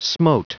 Prononciation du mot smote en anglais (fichier audio)
Prononciation du mot : smote